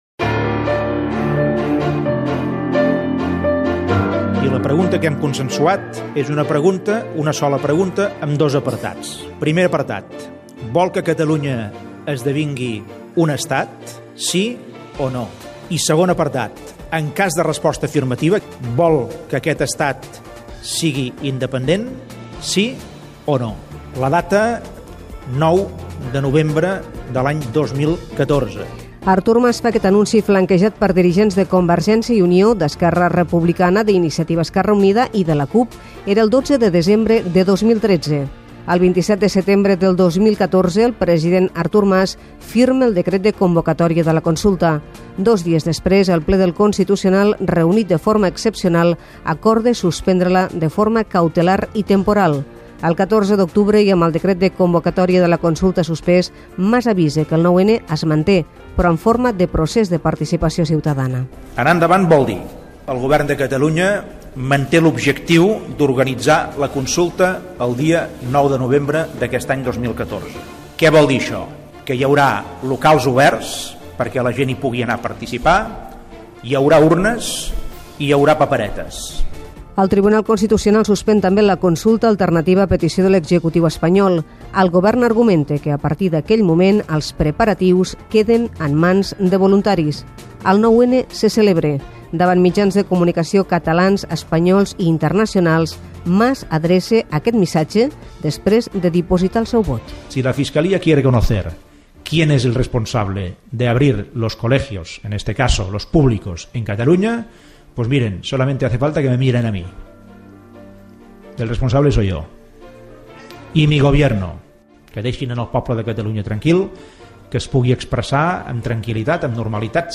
Reportatge previ a l'inici del judici, el dia 6 de febrer de 2017, del Procés de Participació Ciutadana convocat per la Generalitat de Catalunya i fet el 9 de novembre de 2014: cronologia dels fets i diverses declaracions del president de la Generalitat Artur Mas
Informatiu